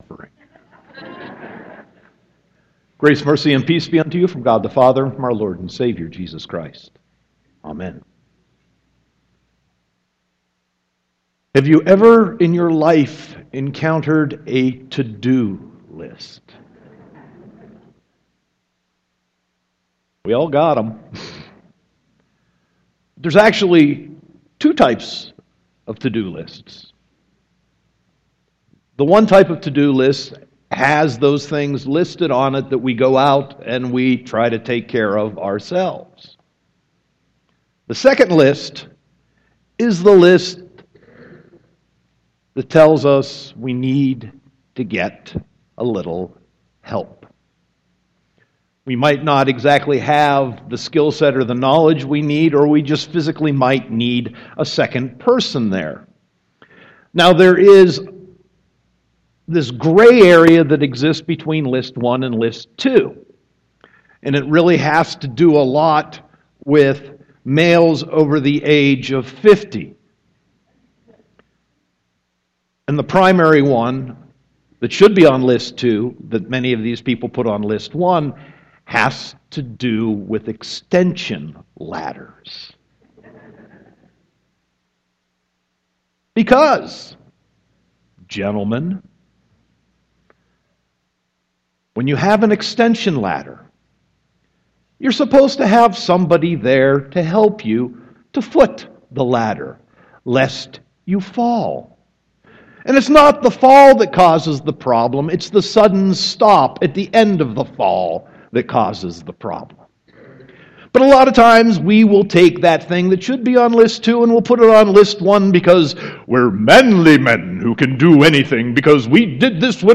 Sermon 5.1.2016